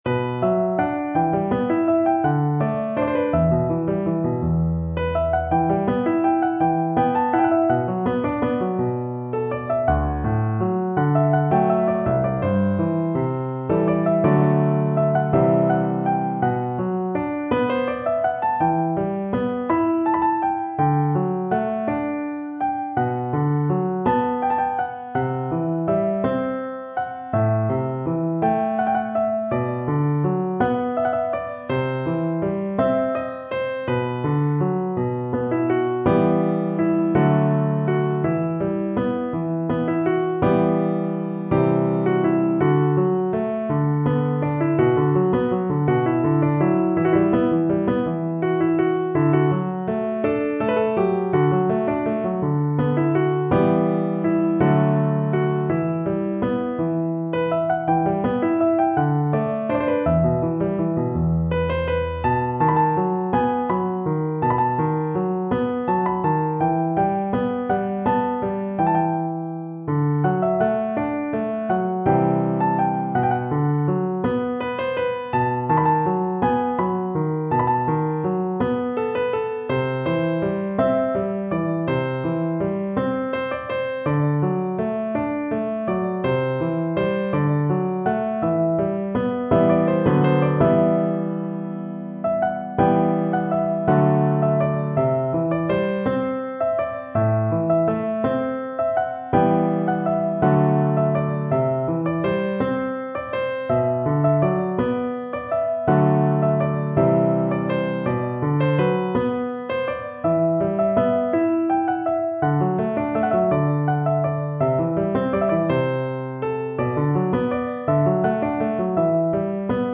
سطح : متوسط